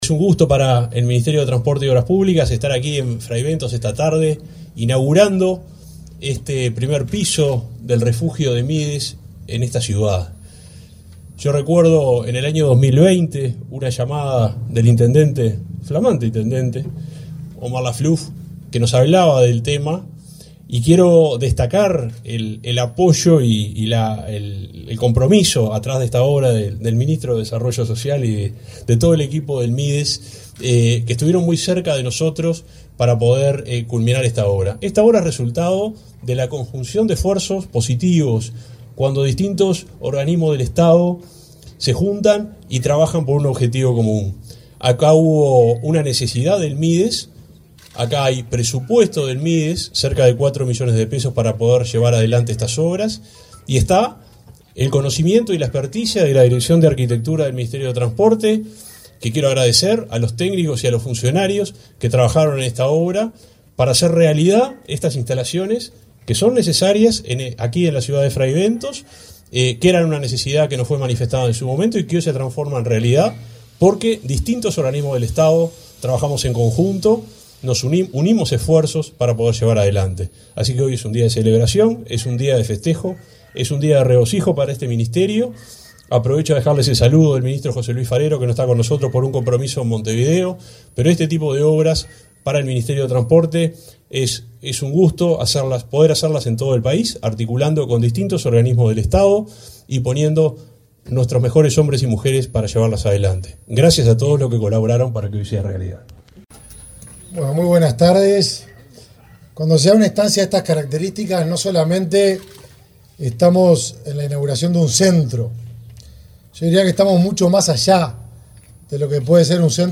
Palabras del subsecretario de Transporte y del ministro de Desarrollo Social